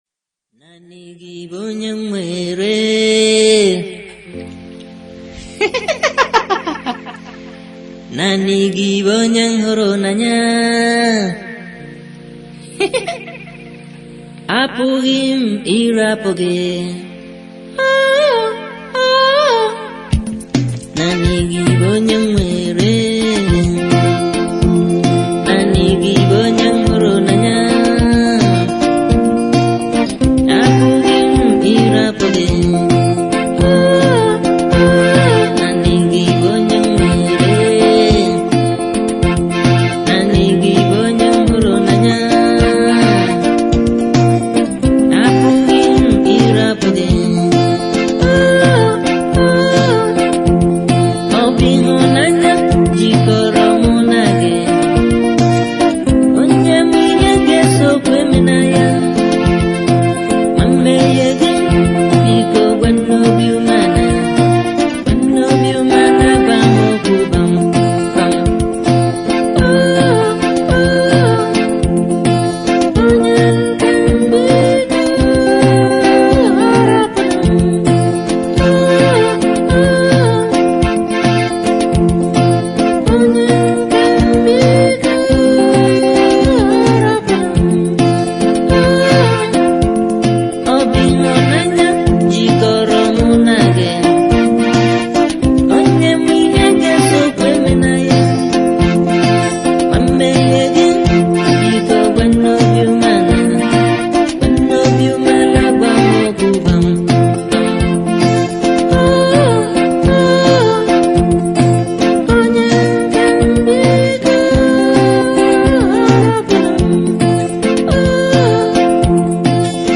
October 16, 2024 admin Highlife Music, Music 0